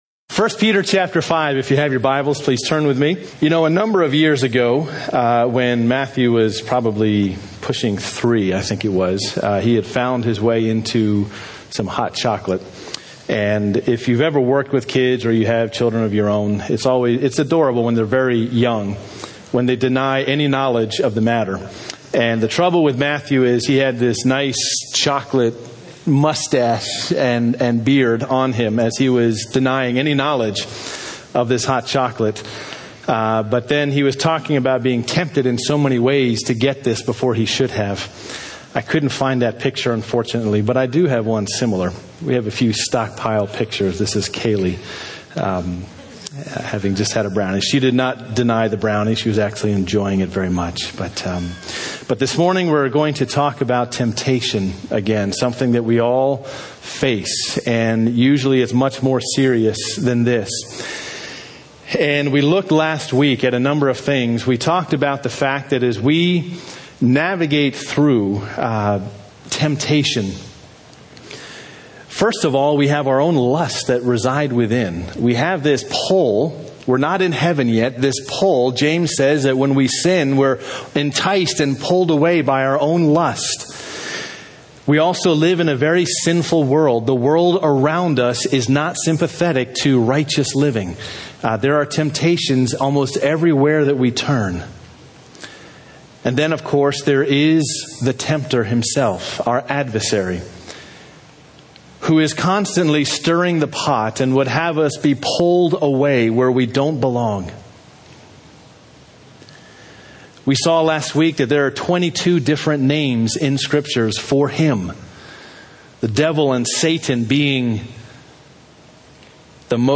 Sermons (audio) — Derwood Bible Church